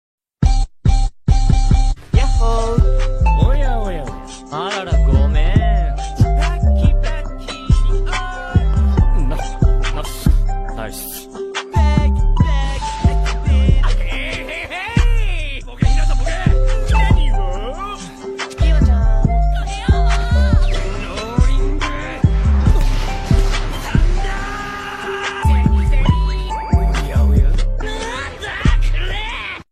Genre: Nhạc chuông báo thức